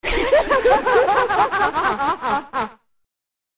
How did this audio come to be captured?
Folly Leave No Reverb Botão de Som